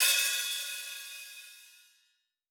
TC2 Live Hihat15.wav